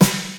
• Eighties Noisy Punk Snare F# Key 469.wav
Royality free snare sound tuned to the F# note. Loudest frequency: 2488Hz
eighties-noisy-punk-snare-f-sharp-key-469-qi1.wav